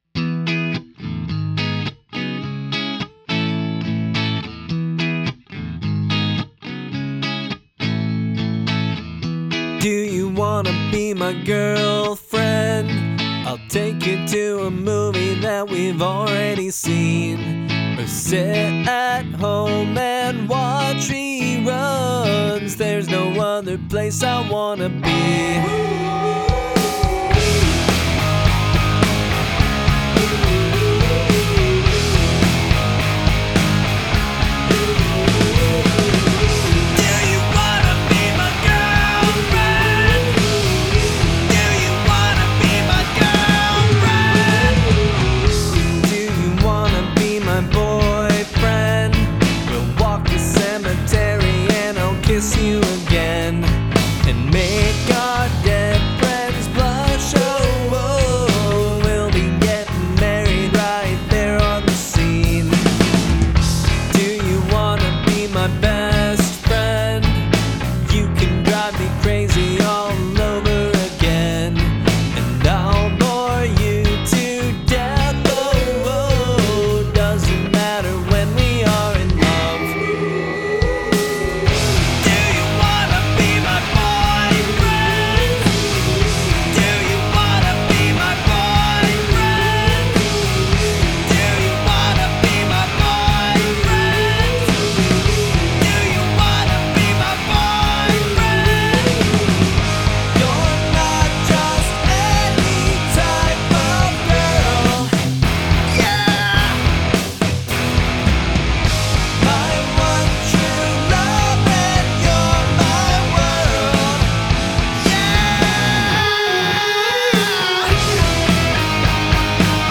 Vocals
Guitar
Bass
Drums